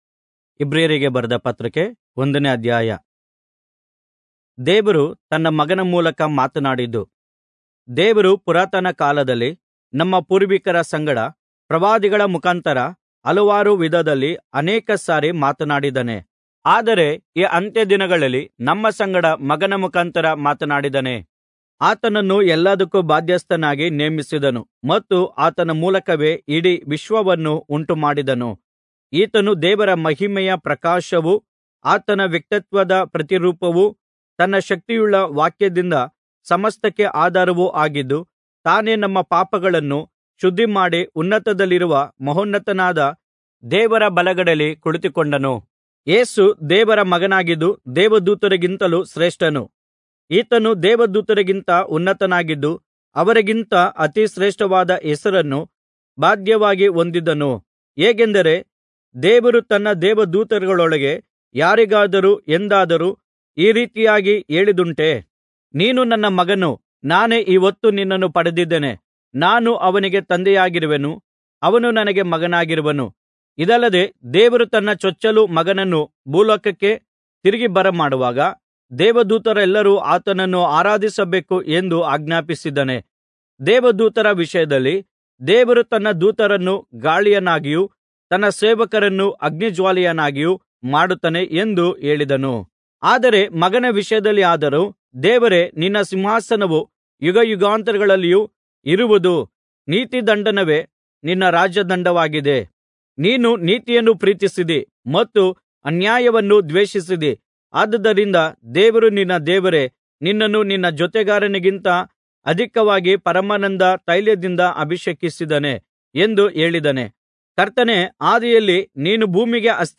Kannada Audio Bible - Hebrews 3 in Irvkn bible version